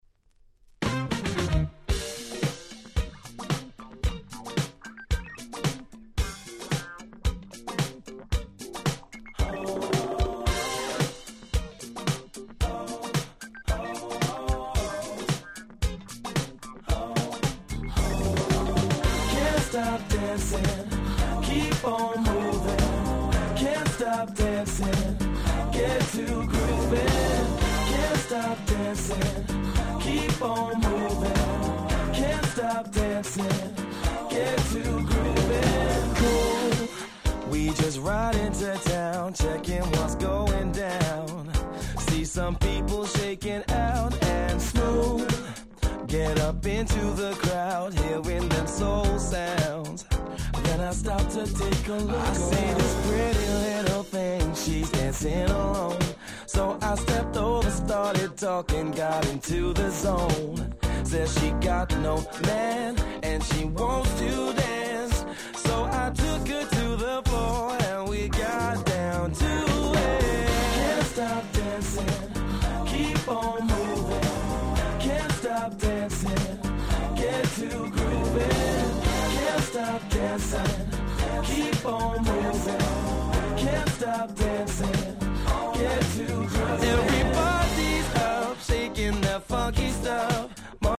05' Super Nice UK R&B/Neo Soul !!